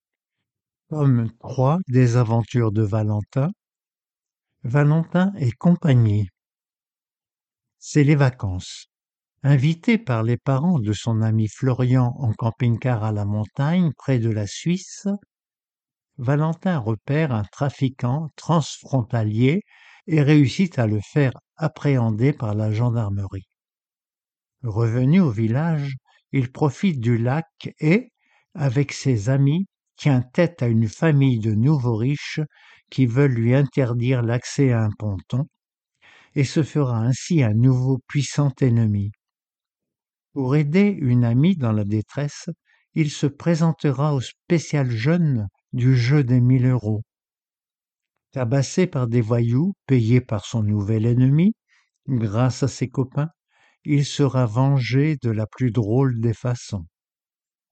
Romans audios pour adultes mal-voyants